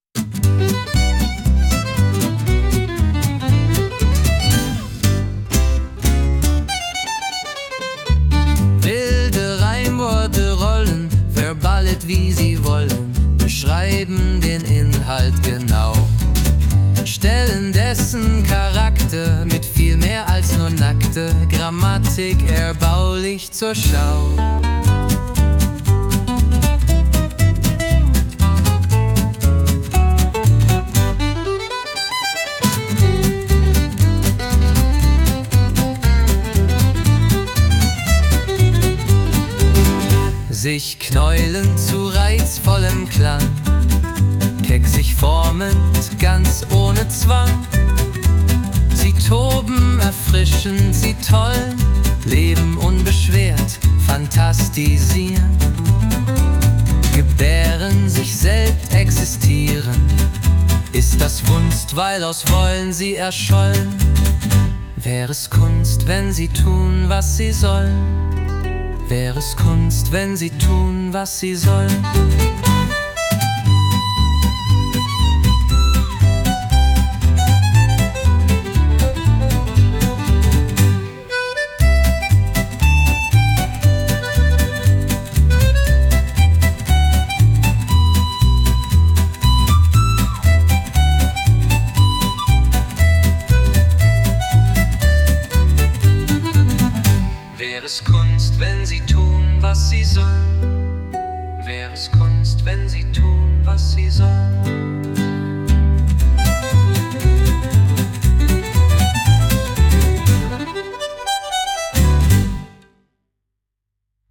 KI-gestütztes Audiodesign